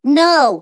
synthetic-wakewords
ovos-tts-plugin-deepponies_Bart Simpson_en.wav